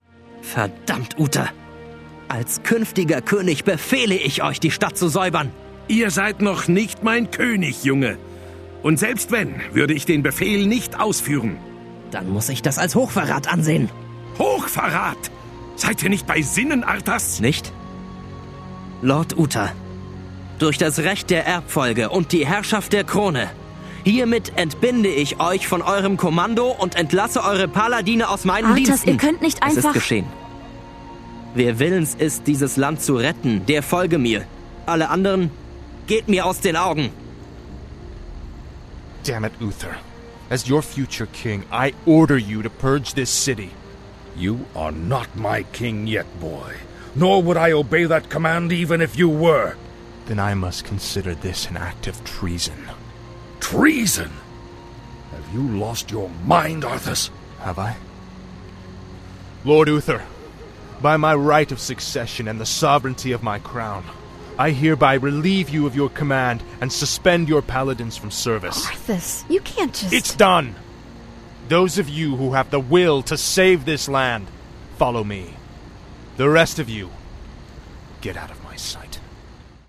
in Deutsch und dann im englischen Original anhört. Hier im Beispiel sind die deutschen Stimmen an sich nicht mal schlecht, aber die Betonungen bleiben weit hinter der englischen Aufnahme zurück.